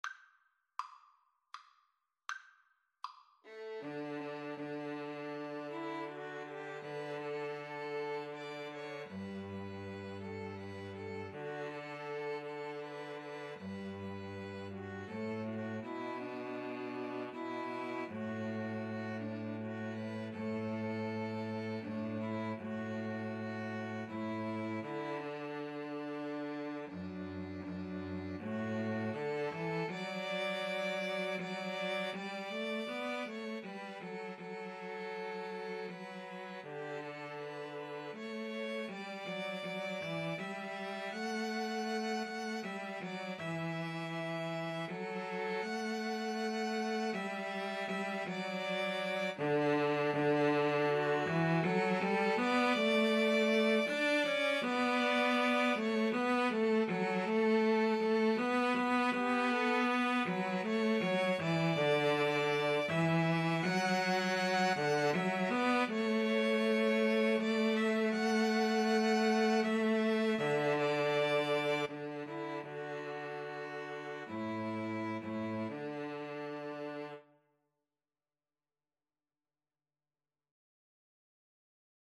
G major (Sounding Pitch) (View more G major Music for String trio )
Andante